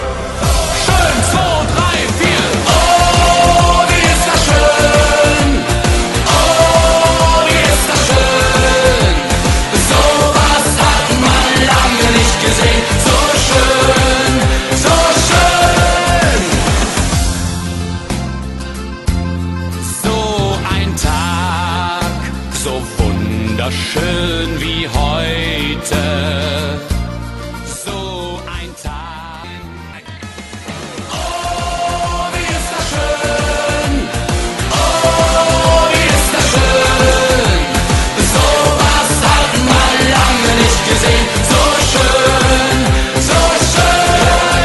Gattung: Moderner Einzeltitel
Besetzung: Blasorchester
In dieser mitreißenden und erfrischenden Shuffle-Version